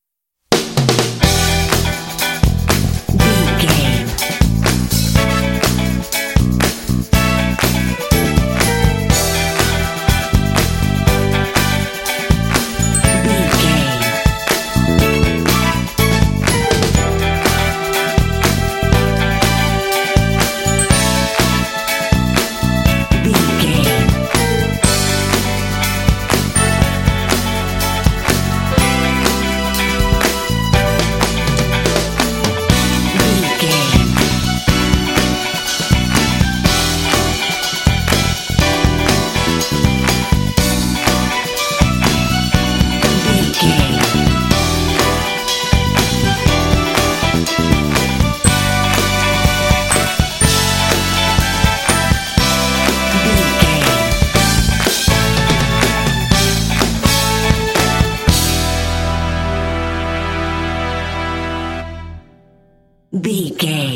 Aeolian/Minor
funky
groovy
driving
energetic
lively
strings
bass guitar
electric guitar
drums
brass